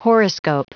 Prononciation du mot horoscope en anglais (fichier audio)
Prononciation du mot : horoscope